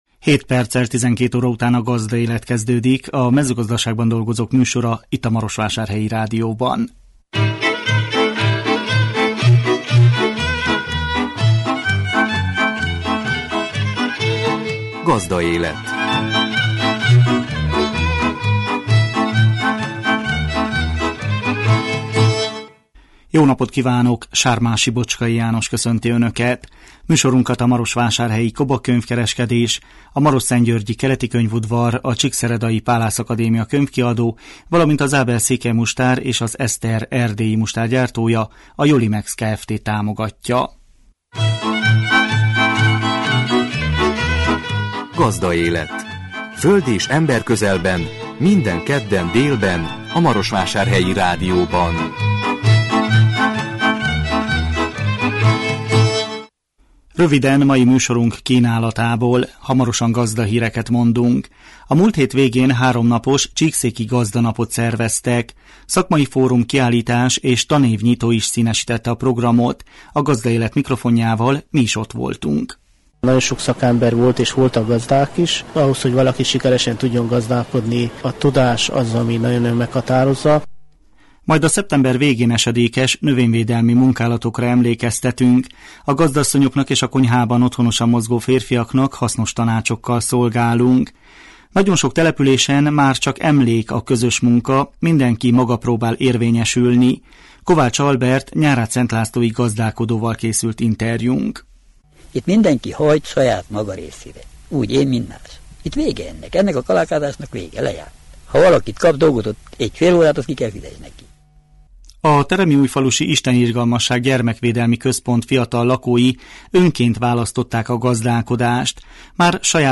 A Gazdaélet mikrofonjával mi is ott voltunk. Majd a szeptember végén esedékes növényvédelmi munkálatokra emlékeztetünk.